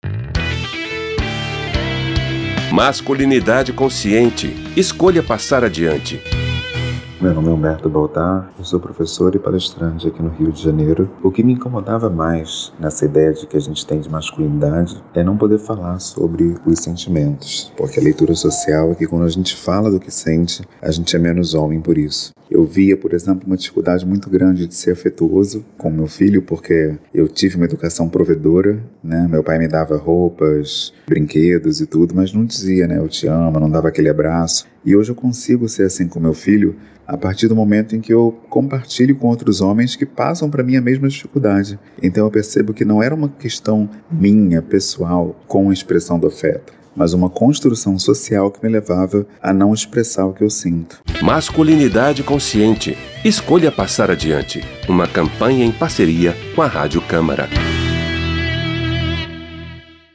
Em 13 depoimentos em áudio e cinco em vídeo com histórias reais de homens que romperam com medos e crenças a respeito da masculinidade, a Rádio Câmara lança a Campanha Masculinidade Consciente – escolha passar adiante.